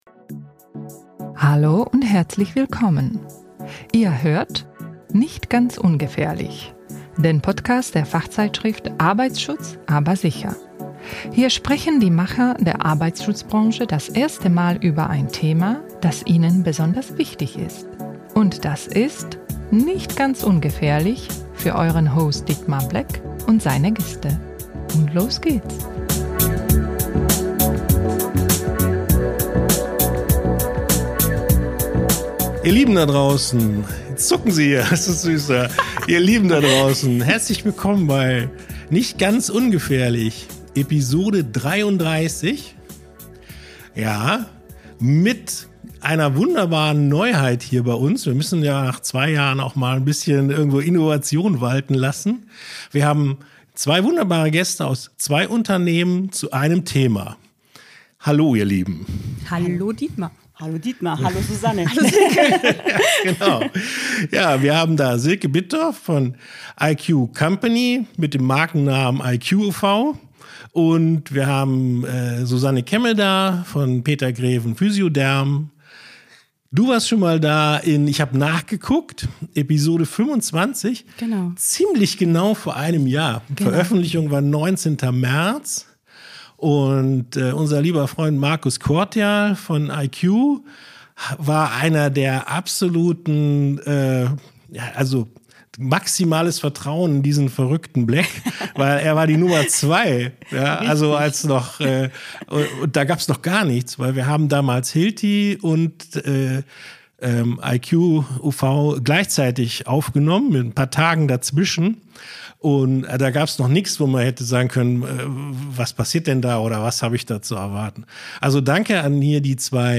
Zwei Expertinnen, zwei Blickwinkel und ein intensiver, und lockerer Austausch rund um ein Thema, das beide verbindet und das an Relevanz nur zunimmt – der wirksame UV-Schutz.